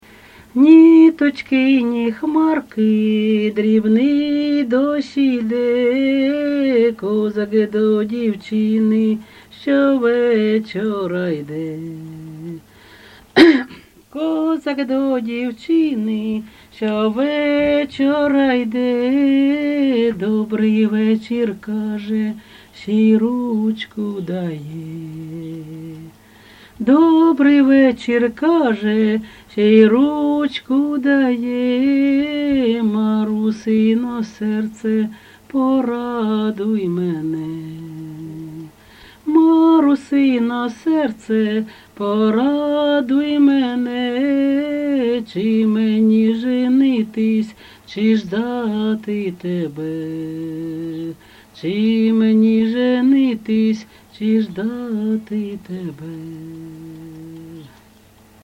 ЖанрПісні з особистого та родинного життя
Місце записус. Серебрянка, Артемівський (Бахмутський) район, Донецька обл., Україна, Слобожанщина